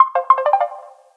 sms2.wav